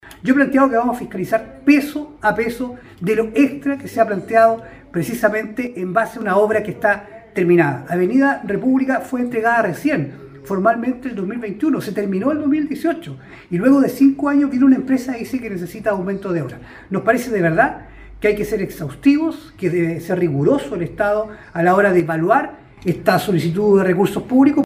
En la última sesión plenaria del Consejo Regional el core por la provincia de Osorno, Francisco Reyes Castro, dijo que fiscalizará cada peso extra que está solicitando la empresa ejecutora de la obra Avenida República, recepcionada con excesivo retraso el 2021, pese a que la importante vía está en marcha desde 2018, hace más de 5 años.